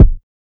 Kicks
pbs - classic jj [ Kick ].wav